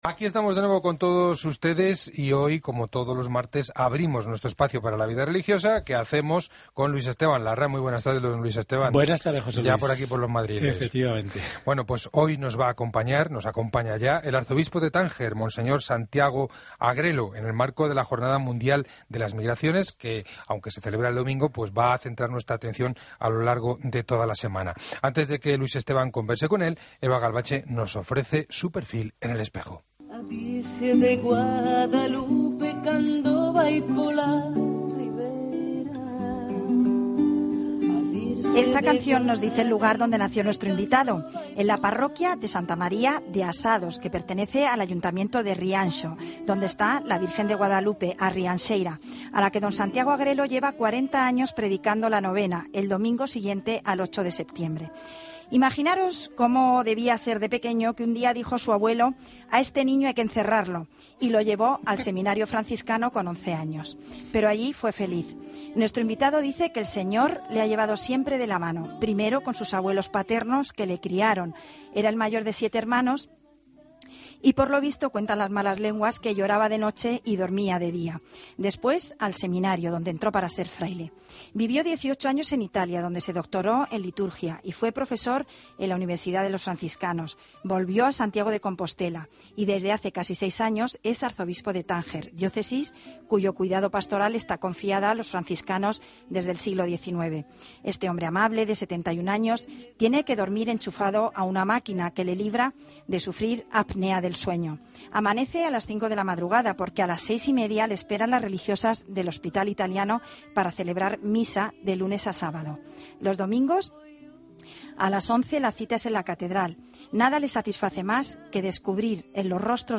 AUDIO: Escucha la entrevista completa a monseñor Santiago Agrelo en 'El Espejo'